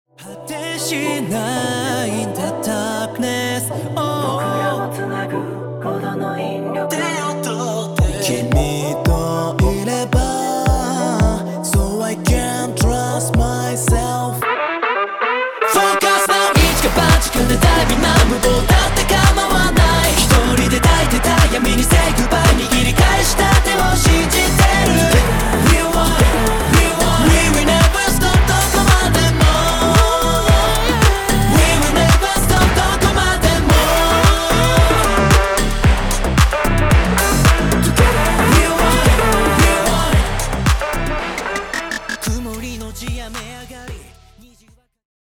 ポップスを軸に様々なジャンルを盛り込んだ